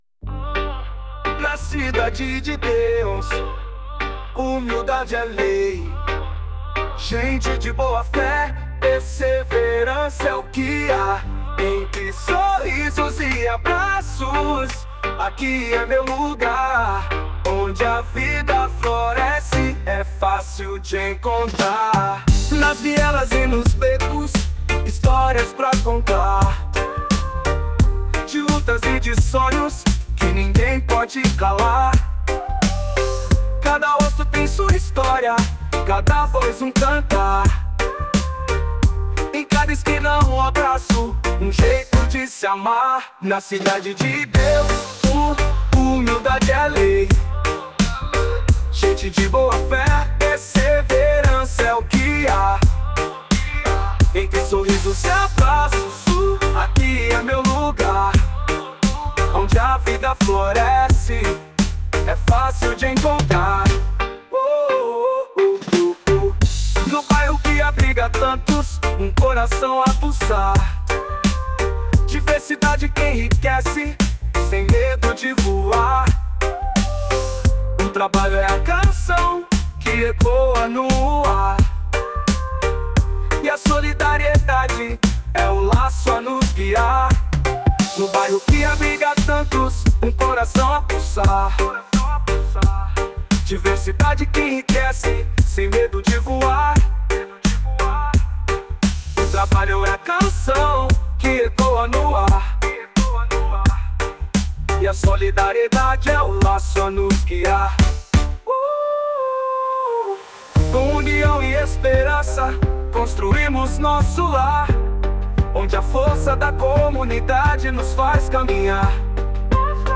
2024-07-22 12:38:35 Gênero: Reggae Views